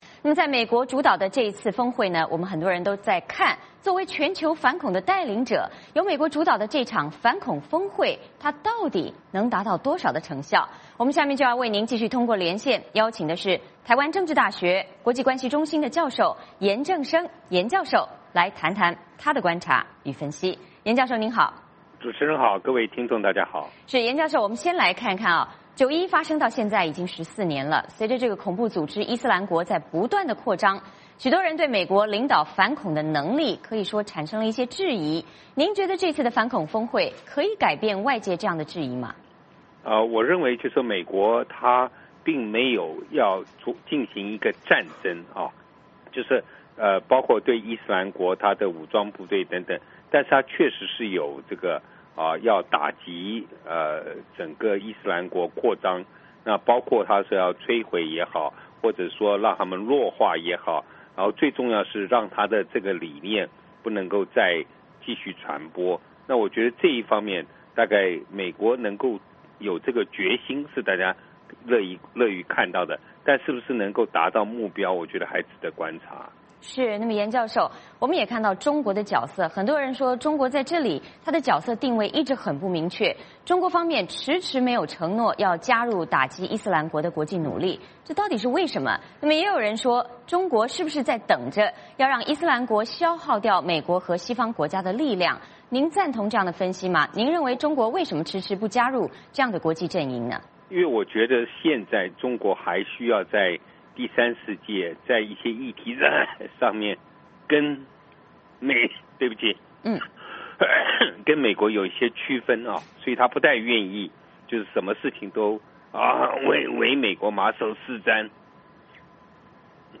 VOA连线：美国反恐峰会能达到多少成效？